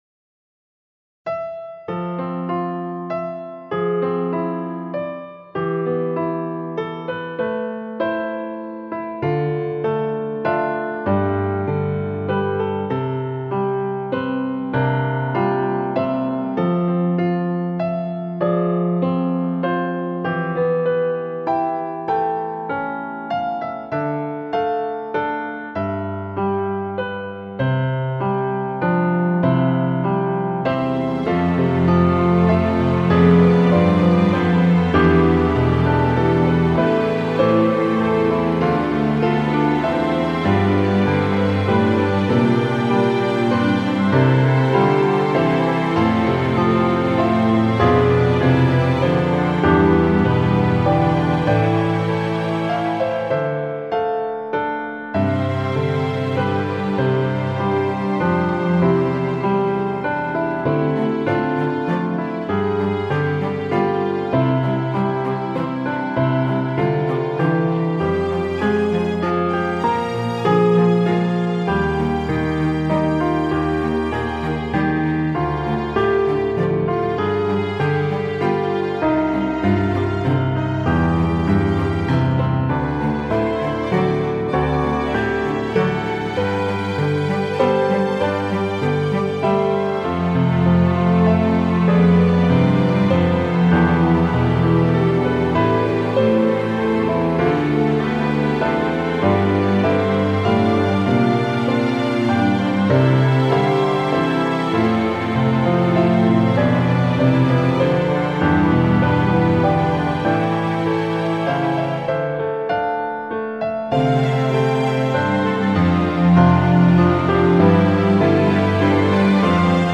피아노
Apiano.mp3